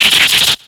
Cri de Séviper dans Pokémon X et Y.